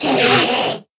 scream2.ogg